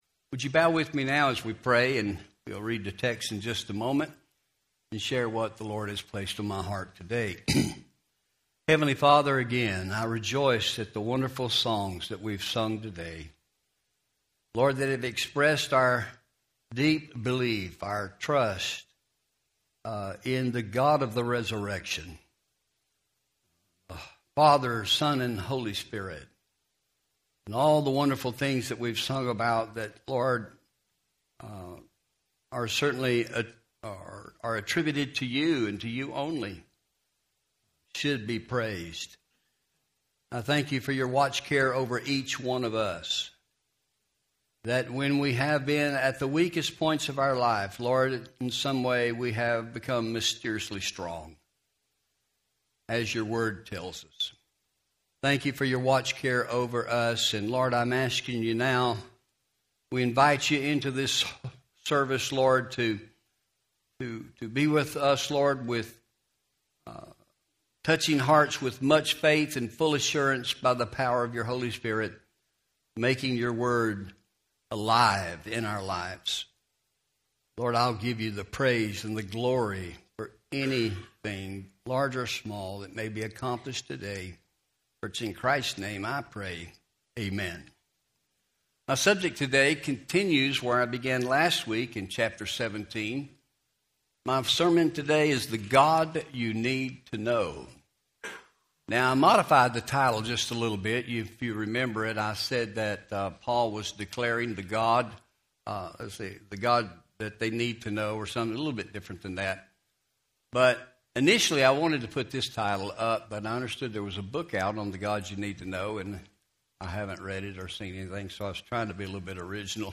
Home › Sermons › The God You Need To Know